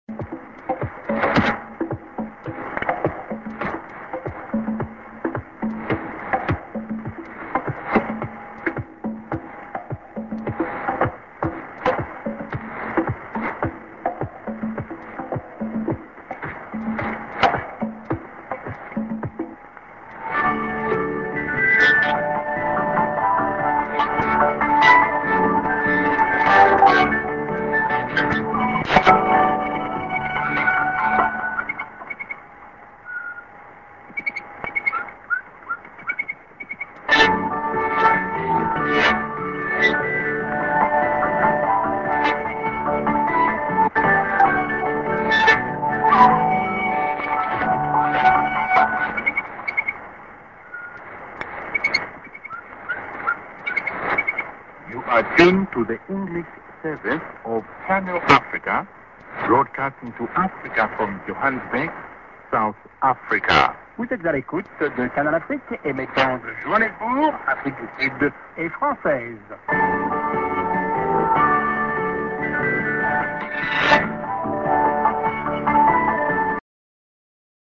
Eng. End duram->IS->ID(man)->IS